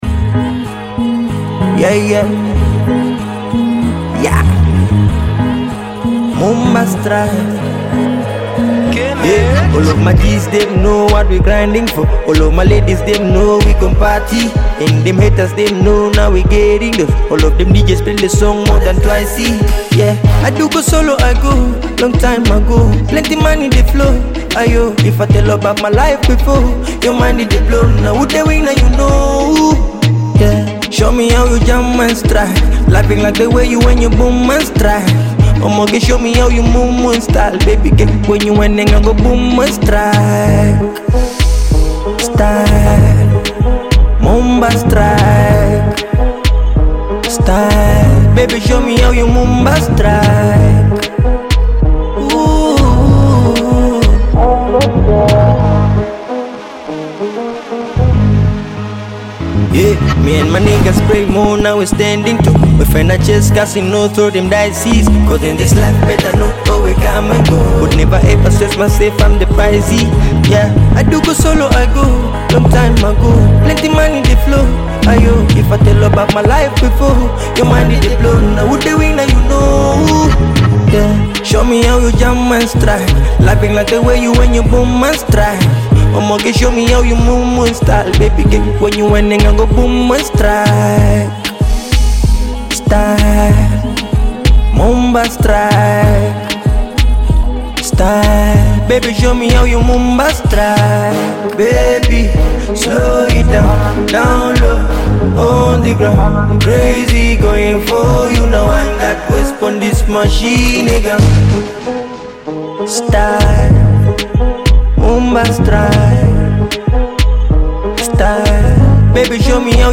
blending Afrobeat rhythms with modern electronic elements.